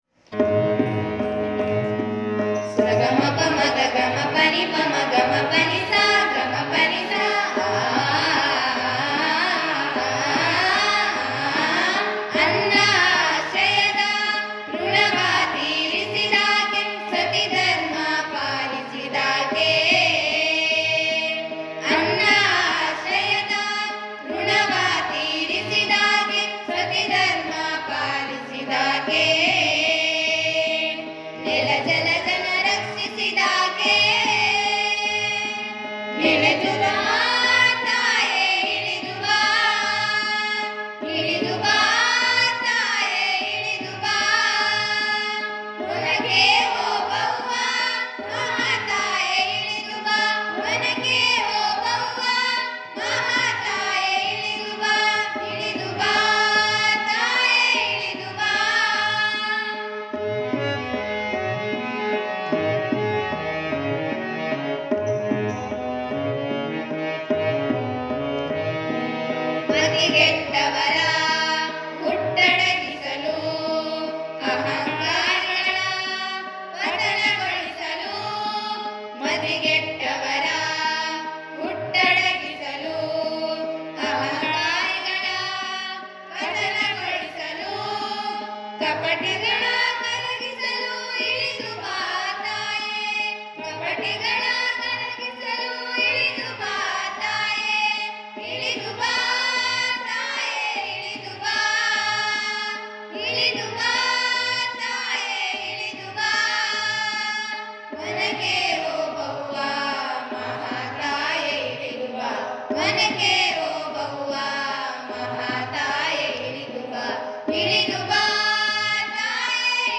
ಸಮೂಹ ಗಾಯನ: ಶ್ರೀ ಮುರಾರ್ಜಿ ದೇಸಾಯಿ ವಸತಿ ಶಾಲೆಯ ಮಕ್ಕಳು, ವಲಭಾಪುರ, ಹ ಬೊ ಹಳ್ಳಿ ತಾ.